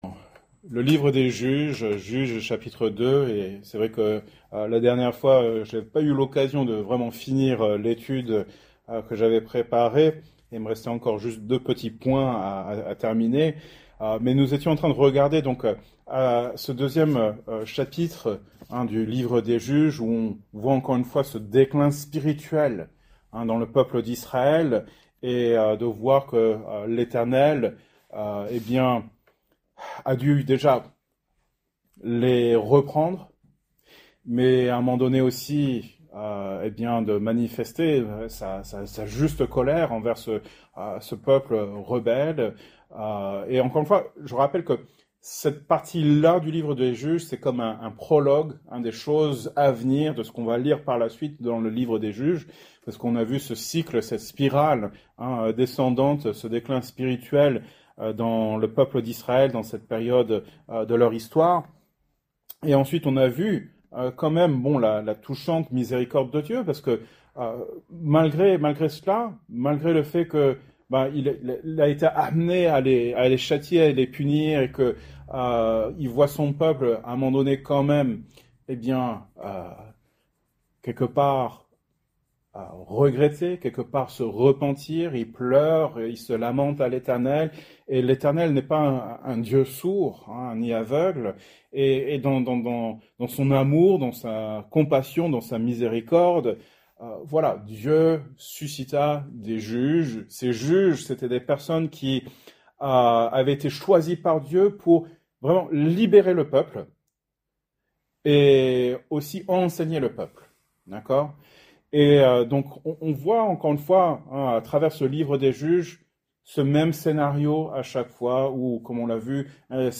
Genre: Etude Biblique